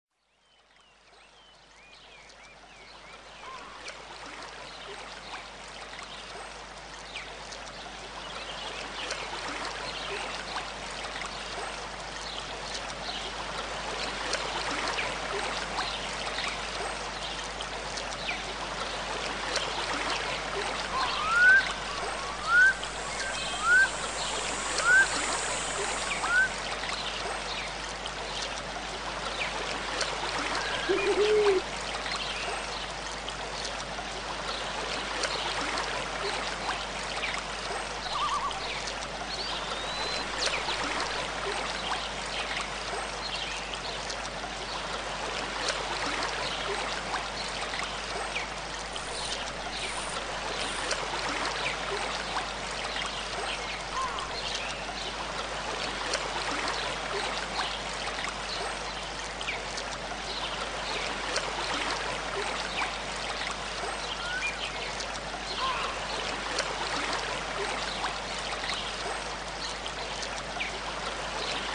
Tranquil Waterfall (1 Hour)
Nature sounds are recorded & designed to help people sleep, allowing you to relax and enjoy the sounds of nature while you rest or focus, with no adverts or interruptions.
Perfect for their masking effects, they are also helpful for people suffering with tinnitus.
Tranquil-Waterfall-Sample.mp3